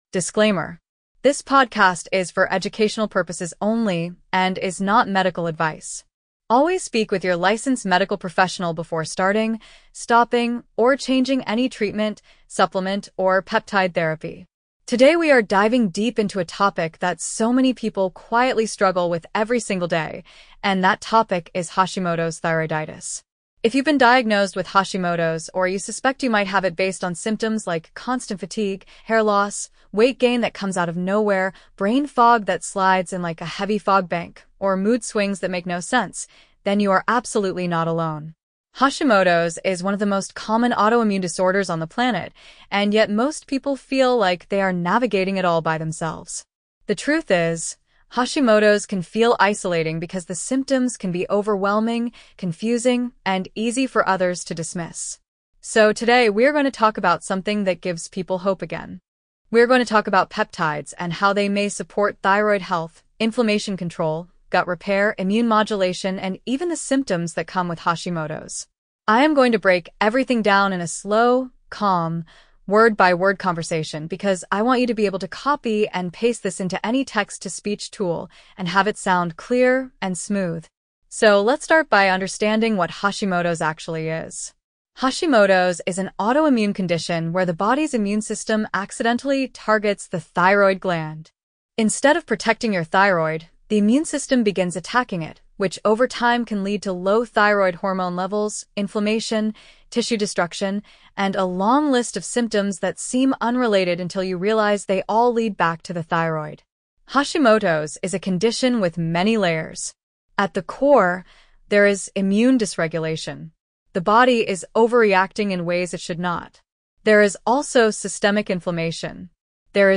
This clear, word by word walkthrough is designed for easy listening and text to speech use, giving you an empowering and understandable look at the peptide tools available for Hashimoto’s support.